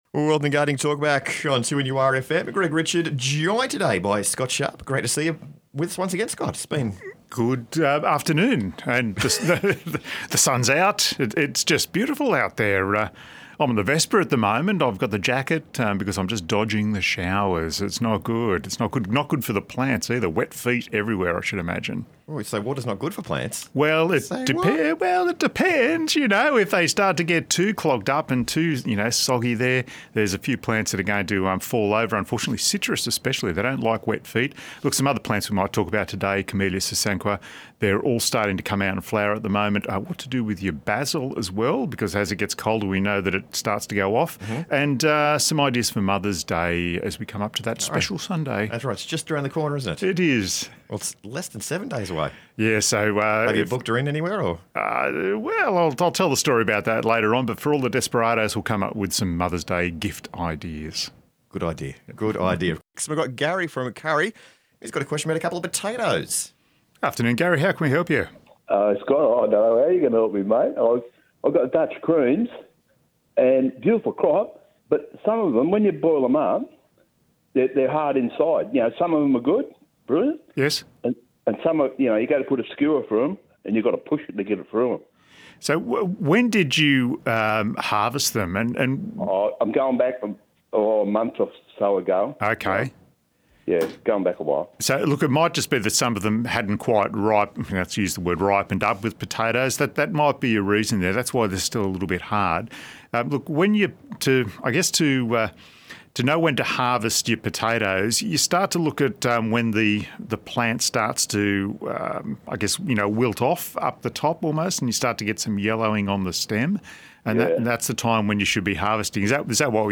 A phone-in show